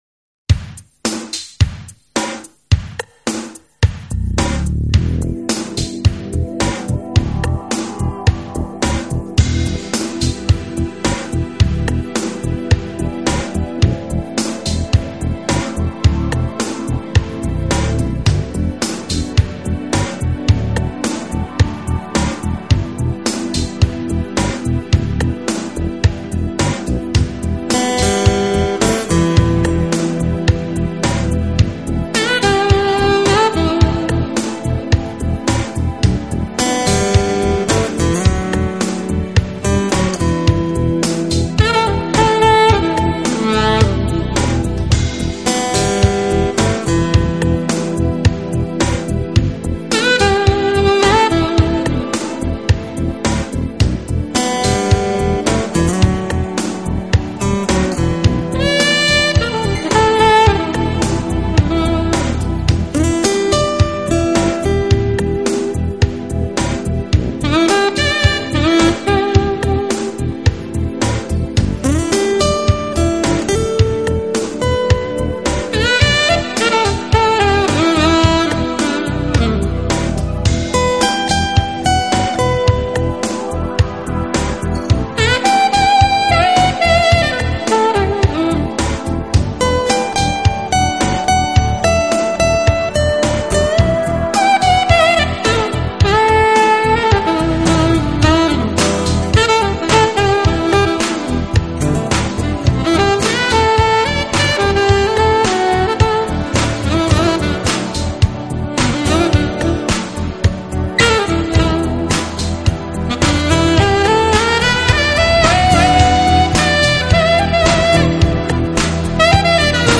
面庞，她吹奏中音萨克斯的技巧也堪称一流。